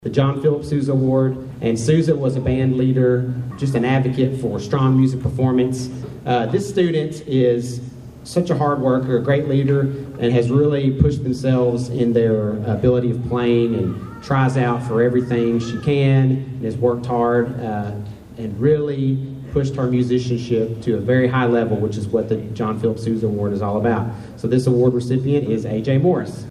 The Caldwell County High School Band of Pride held the end of the Band Banquet Friday night beginning in the high school cafeteria.